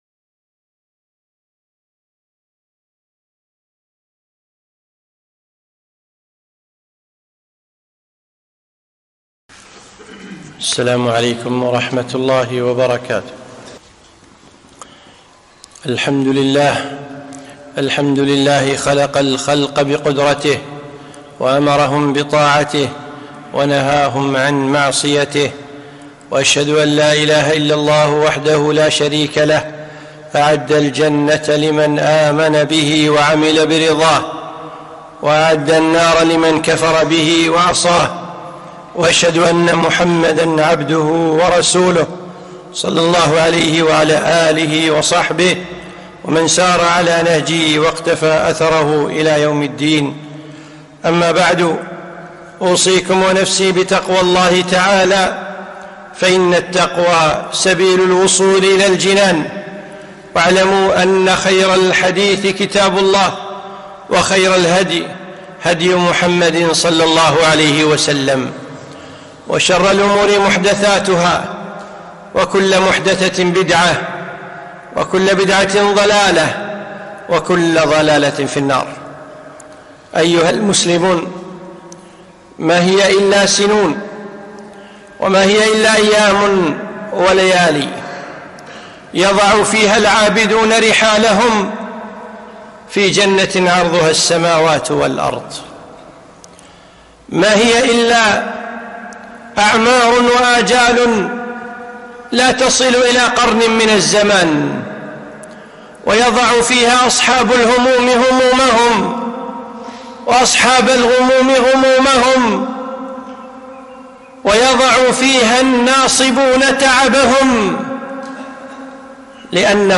خطبة - واهً للجنة